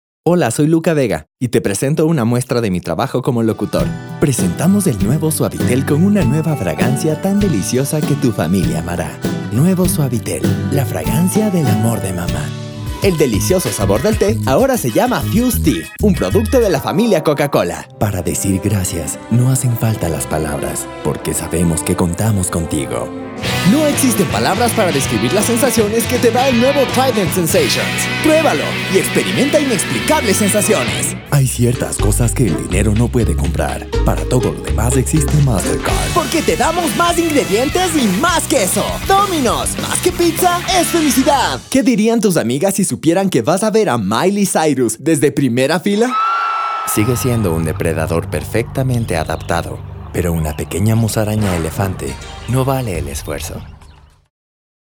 Voz juvenil, profesional, promocional, diferentes acentos y caracterizaciones
Sprechprobe: Werbung (Muttersprache):